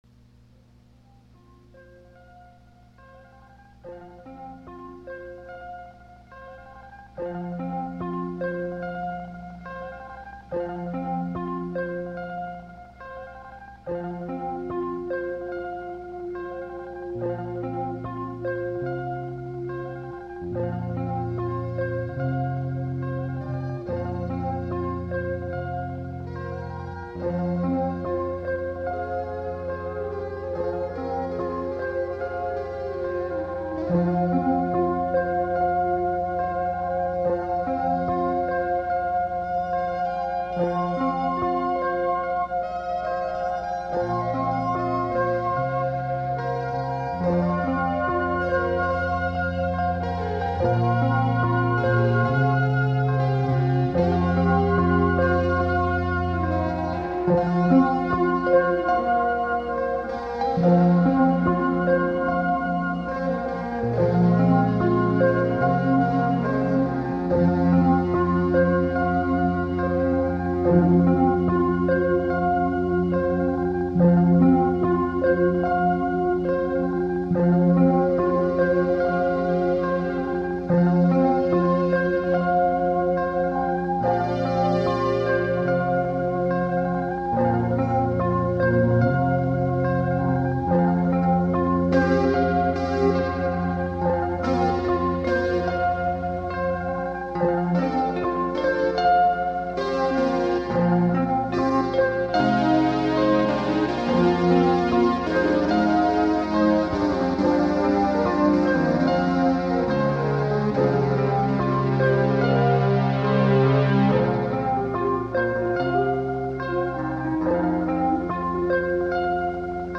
This is major DIY stuff but is pretty damned good.